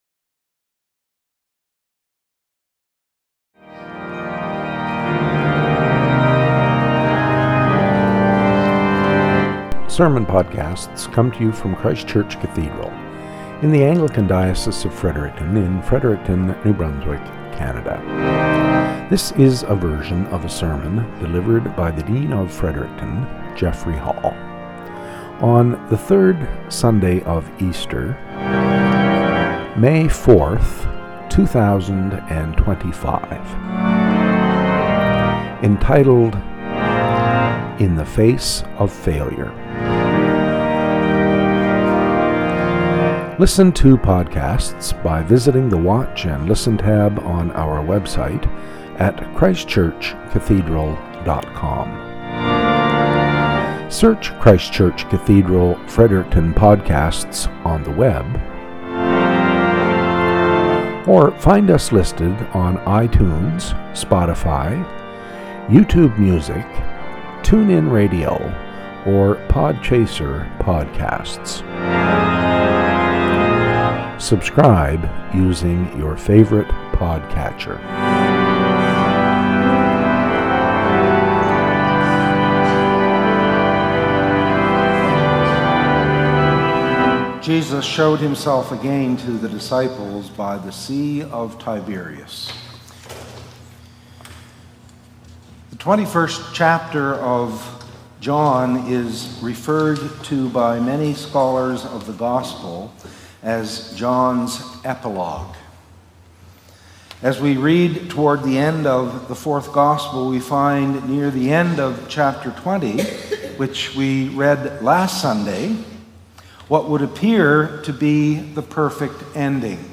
Cathedral Podcast - SERMON -
SERMON - "In the Face of Failure"